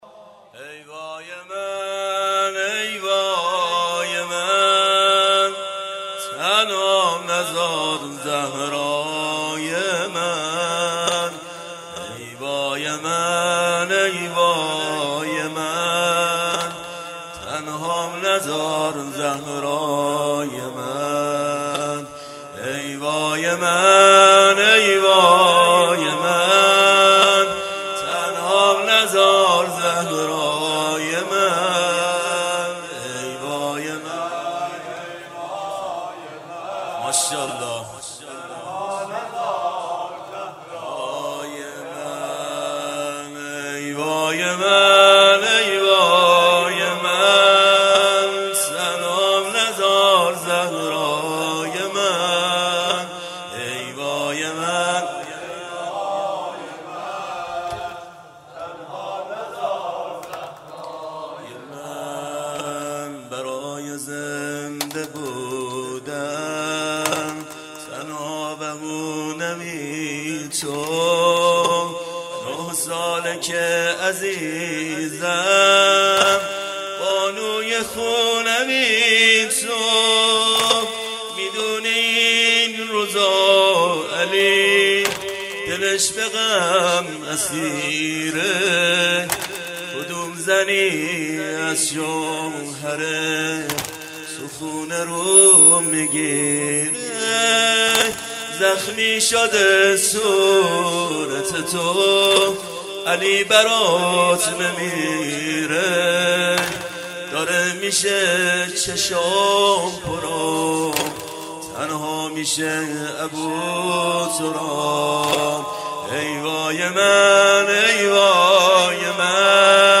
مراسم شب هفتم فاطمیه دوم 93/94(شب تحویل سال نو)
(زمینه)